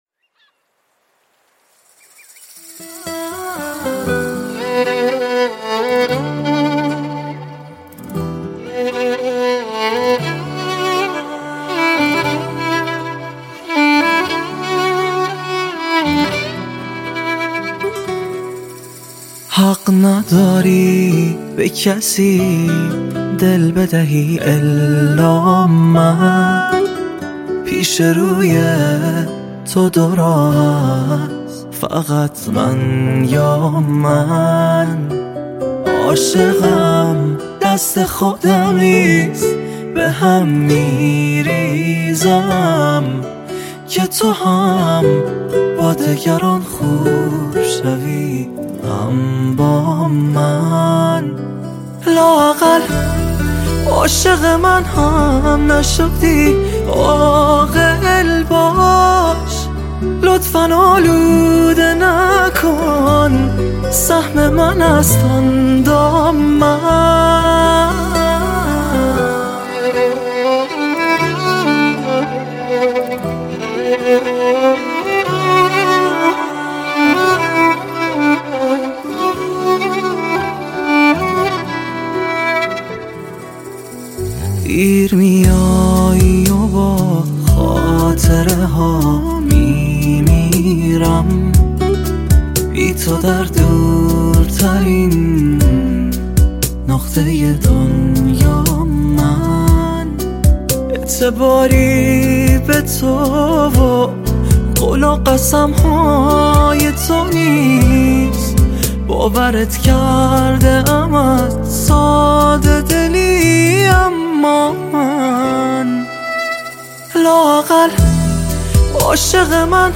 • دسته بندی ایرانی پاپ